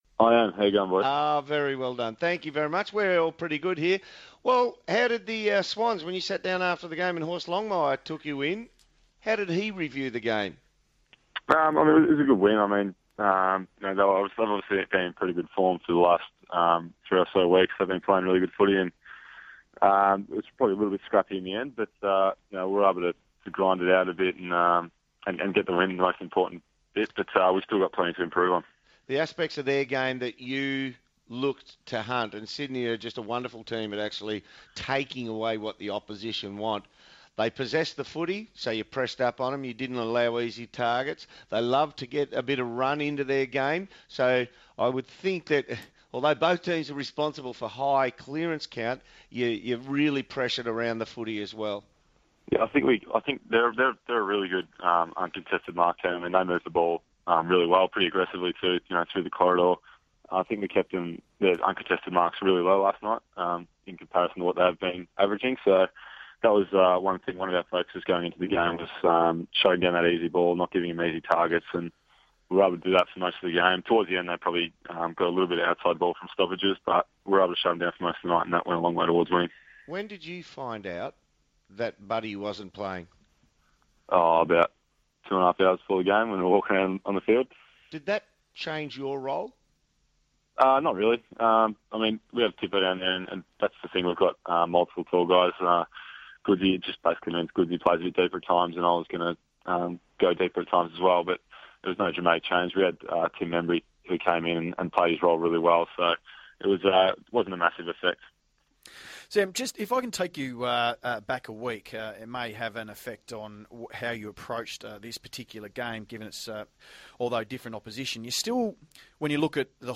Sydney Swans forward Sam Reid appeared on 1116SEN's football coverage on Saturday August 2, 2014